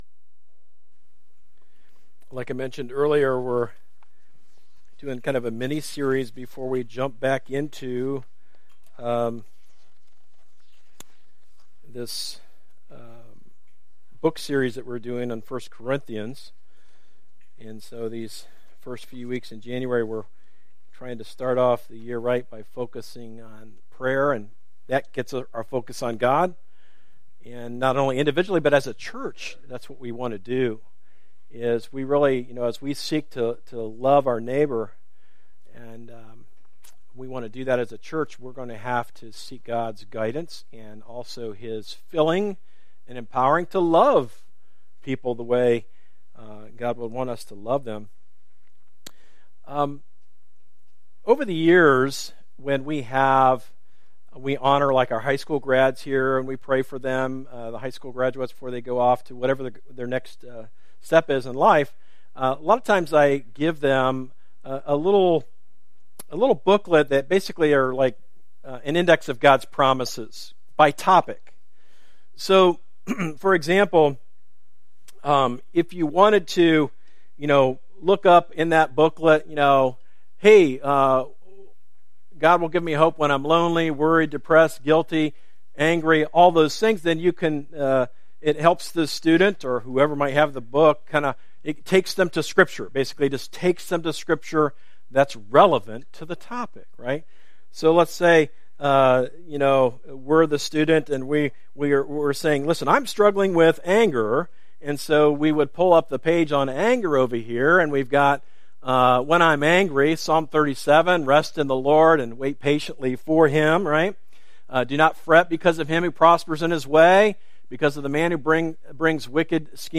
A message from the series "When The Church Prays."